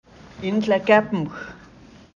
Listen to a pronunciation recording for NłeɁkepmx.